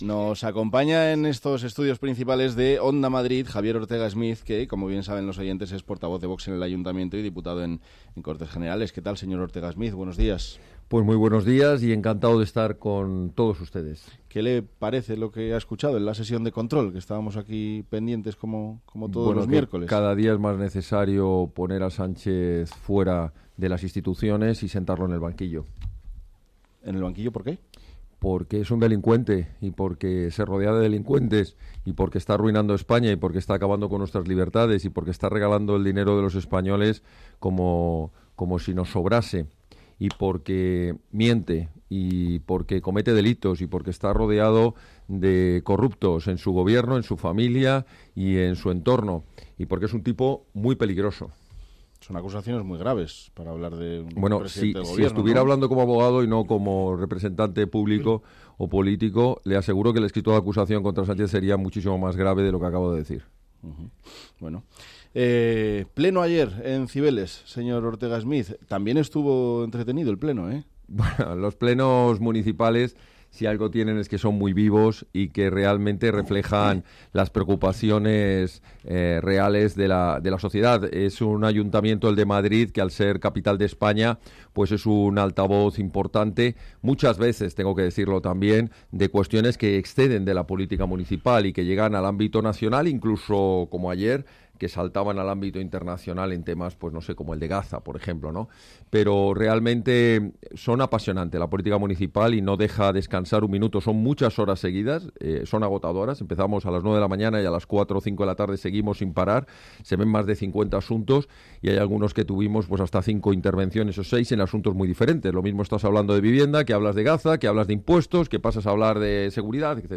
El portavoz de Vox en el Ayuntamiento de Madrid y diputado en el Congreso, Javier Ortega Smith ha visitado los estudios de Onda Madrid donde ha sido entrevistado en Buenos Días, Madrid.
Entrevistas